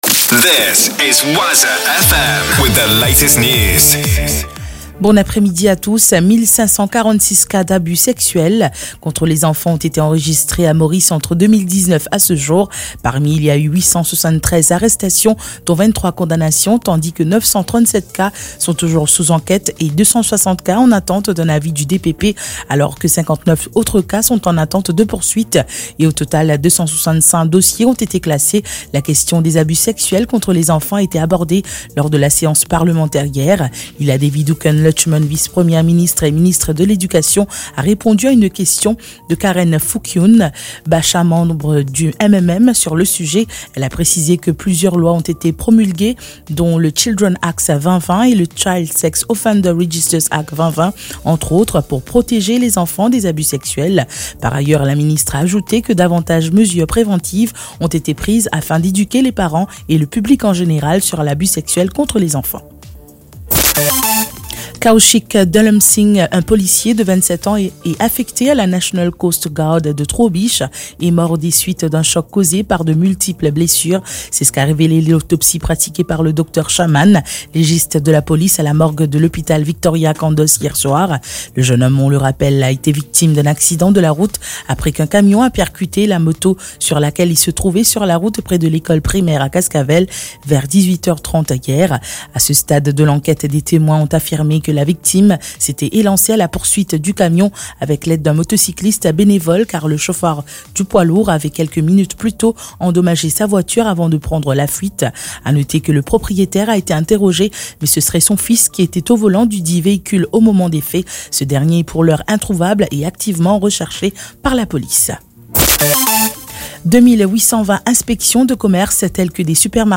NEWS 15H - 22.11.23